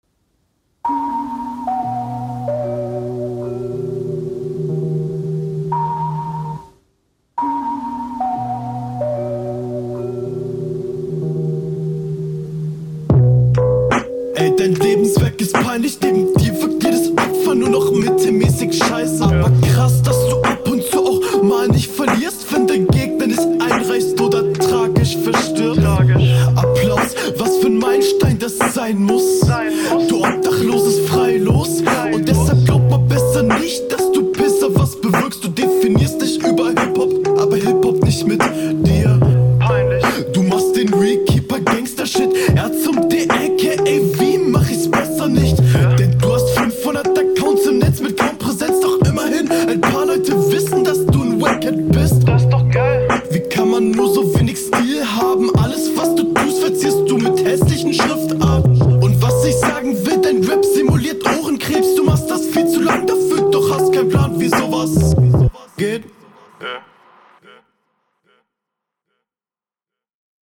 Kranker Beat, Flow zwar relativ basic, aber cooler Stimmeinsatz und gute Pausensetzung.